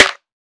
SNARE.46.NEPT.wav